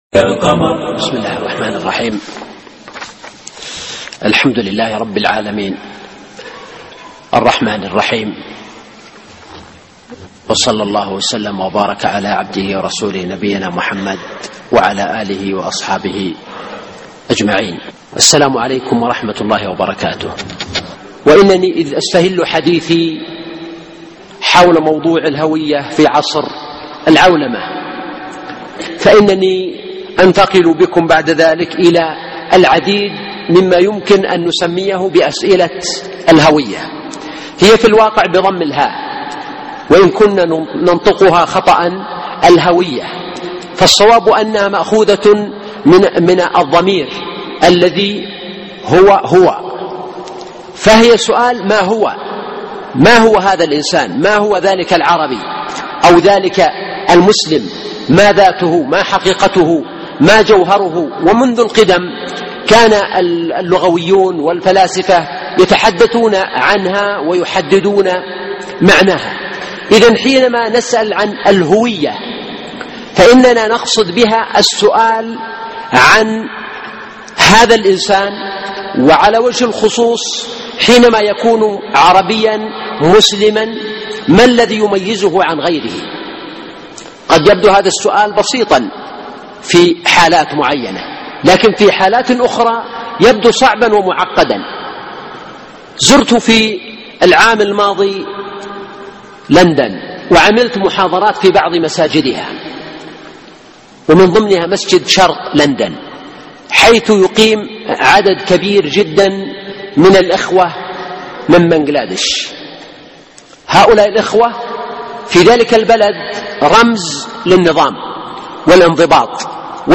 الهوية في عصر العولمة (6/5/2012) محاضرة اليوم - الشيخ سلمان العودة